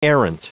Prononciation du mot errant en anglais (fichier audio)
Prononciation du mot : errant